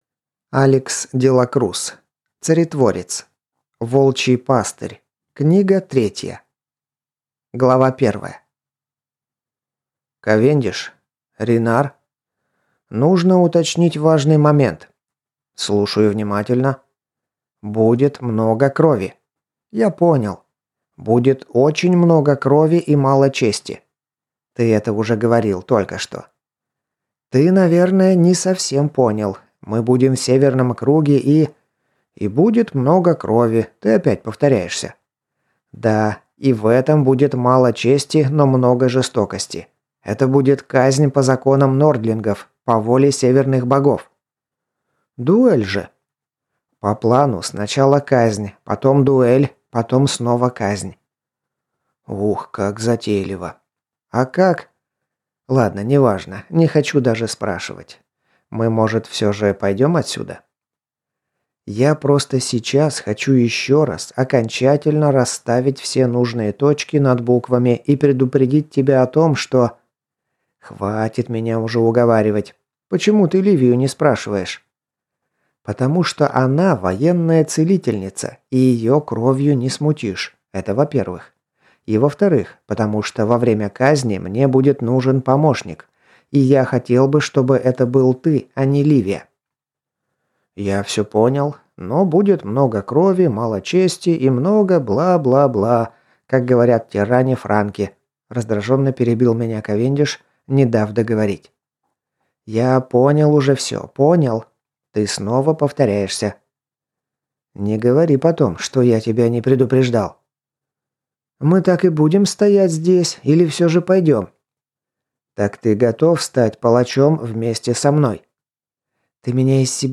Аудиокнига Царетворец. Волчий пастырь. Книга третья | Библиотека аудиокниг